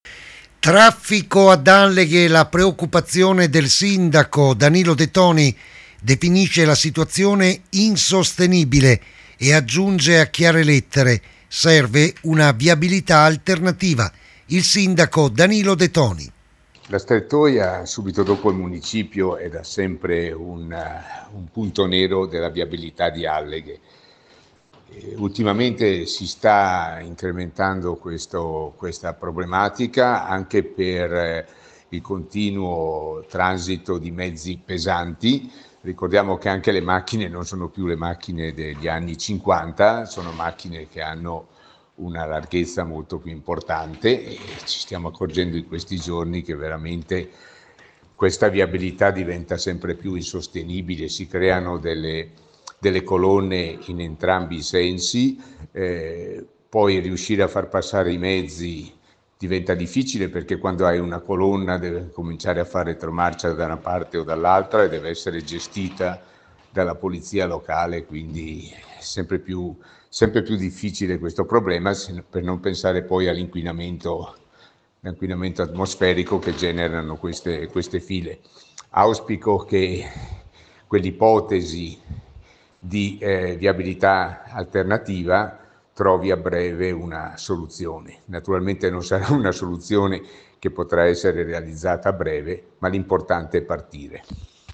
Lunghe code e vita impossibile per i residenti. Il sindaco Danilo De Toni esprime la preoccupazione dei cittadini e annuncia un’azione incisiva verso Governo e Regione per chiedere un’accelerazione sulla viabilità alternativa ipotizzata da tempo.